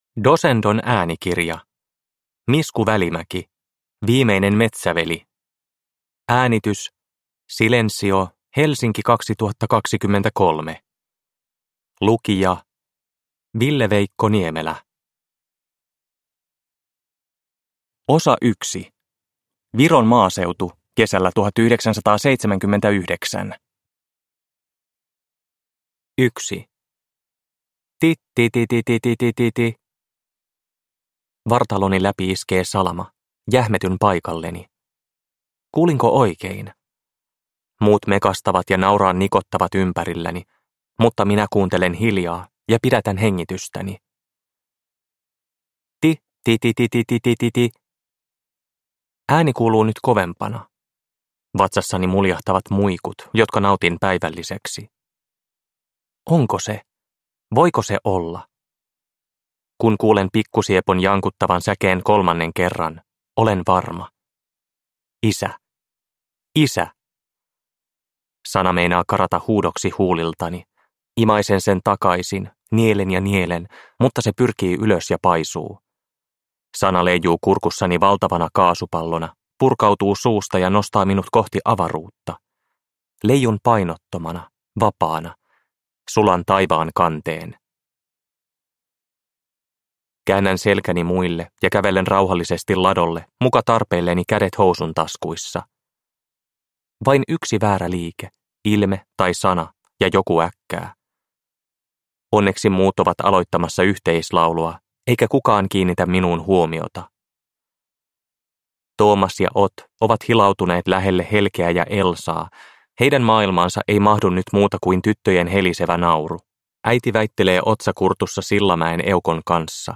Viimeinen metsäveli – Ljudbok – Laddas ner